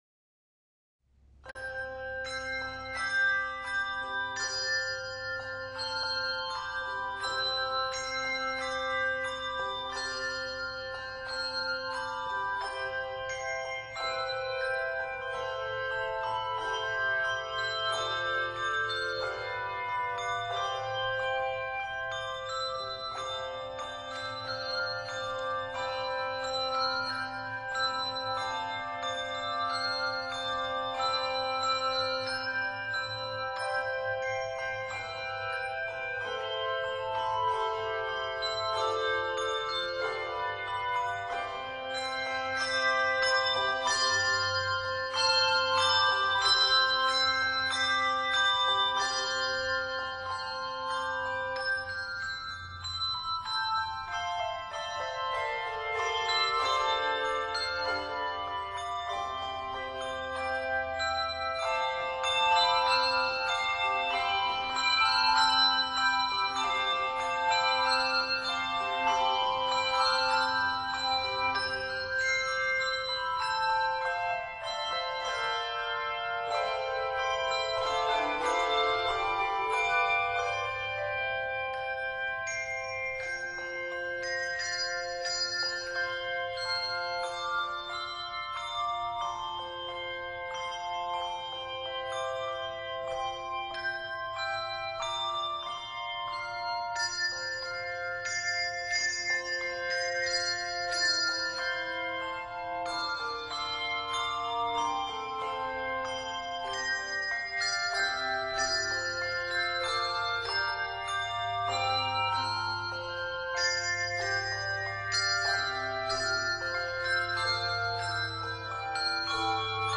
Octaves: 3